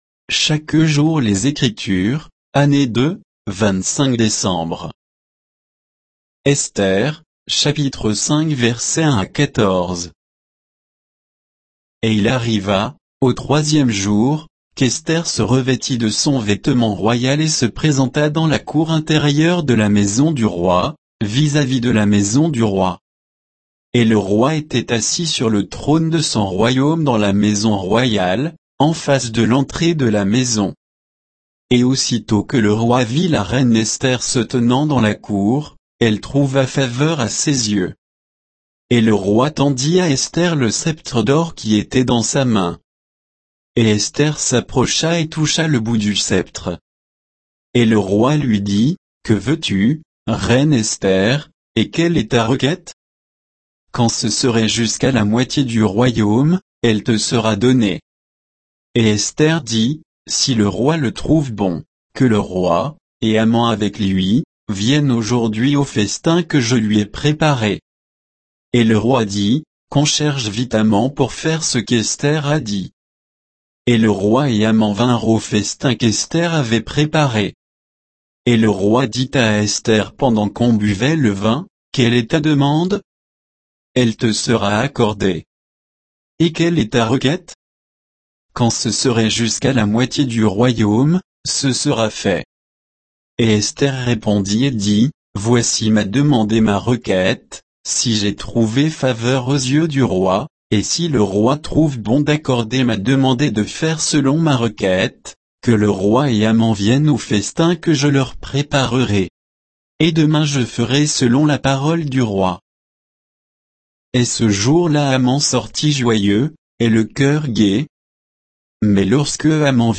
Méditation quoditienne de Chaque jour les Écritures sur Esther 5